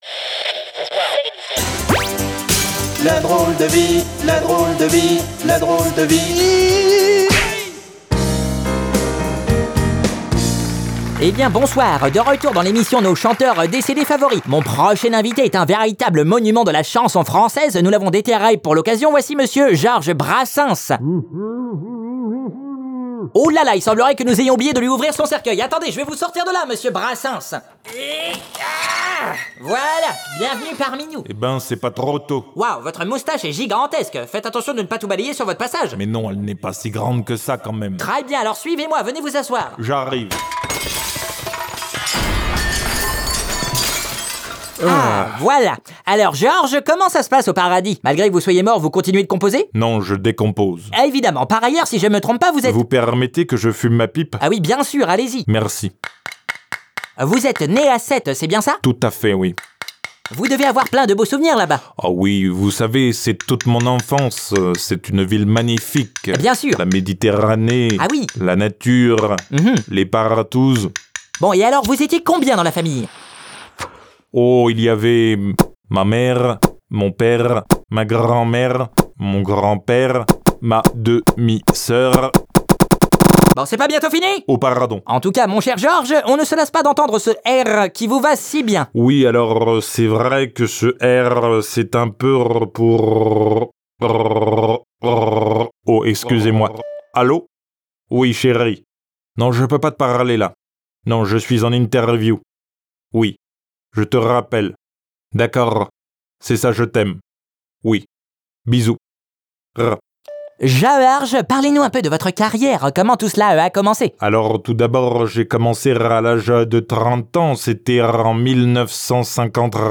ladroledevie018interviewgeorgesbrassens.mp3